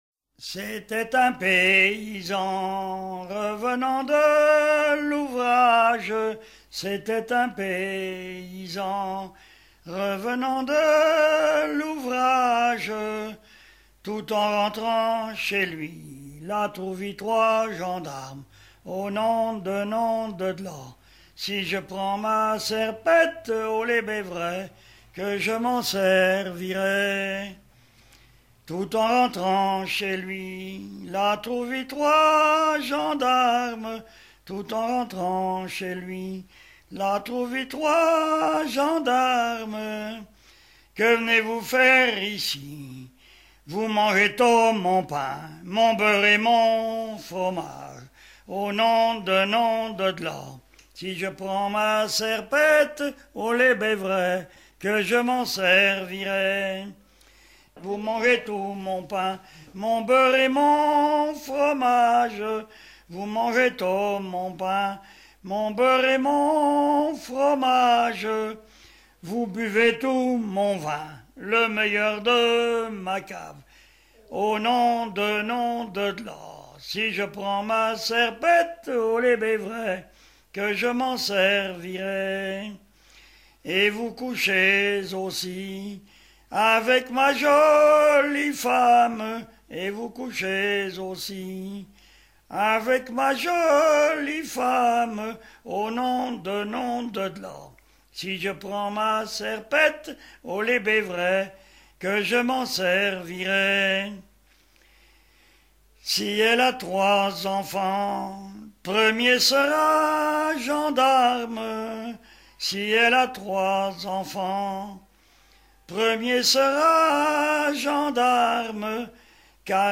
Boissière-de-Montaigu (La)
Genre laisse
Pièce musicale éditée